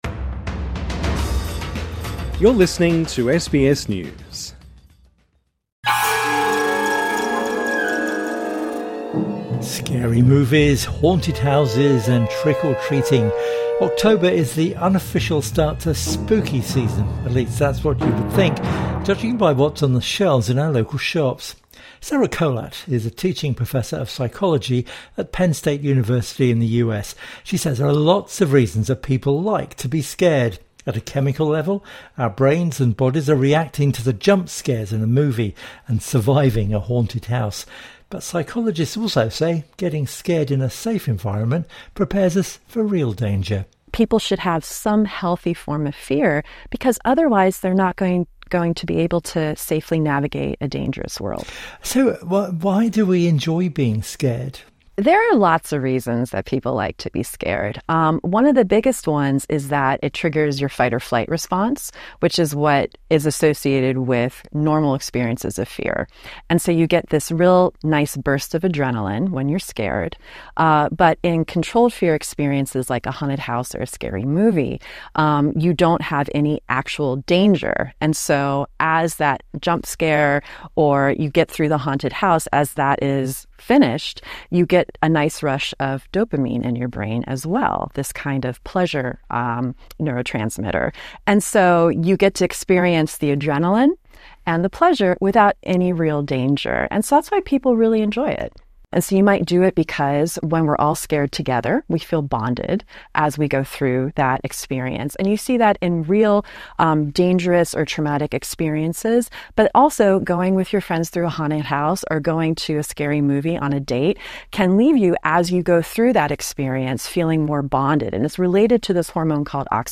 INTERVIEW: Everyone loves a ghost train: but why do we love being scared?